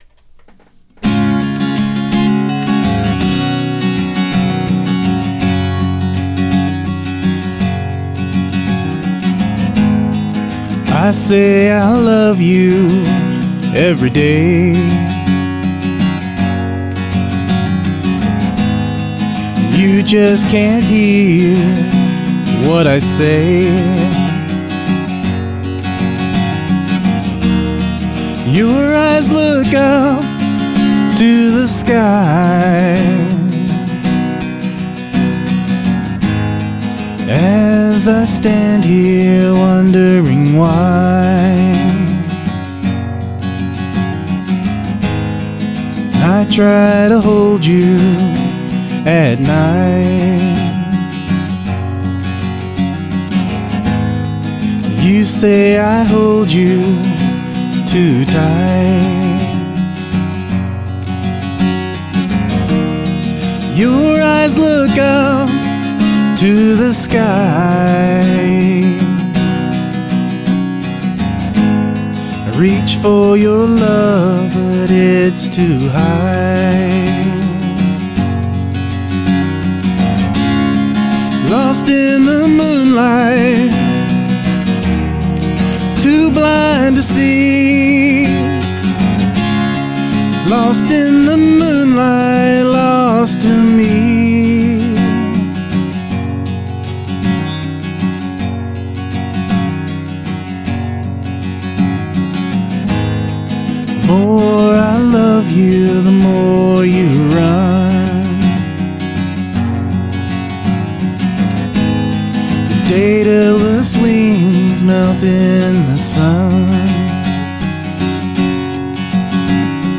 A sweet, full sound out of that guitar of yours. The vocals are excellent and the lyrics are good.
Great sound!!! Really well played and sung!!!
It really sounds nice and full and your vocals are amazing and very professional.
I recorded it line in (built in mic) on one track of the BR900 and then through a at2020 condenser on another track at the same time.
In reaper I panned each of the acoustic rhythm takes hard to one side, one left one right.
If you listen you can tell that one side is more percussive (you can hear the plectrum hitting the strings.)
I noticed also your record is "hot" yet there is no distortion.